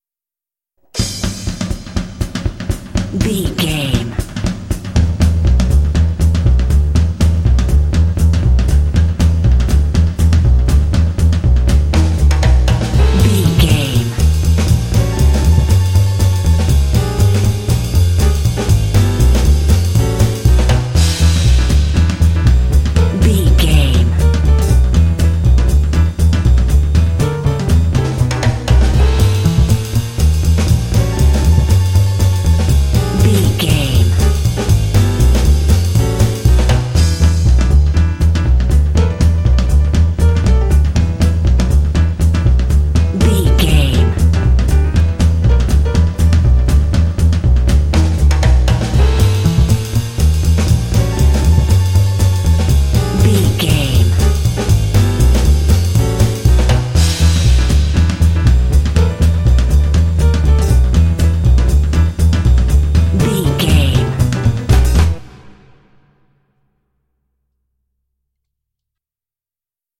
Uplifting
Aeolian/Minor
Fast
driving
energetic
lively
cheerful/happy
drums
double bass
piano
big band
jazz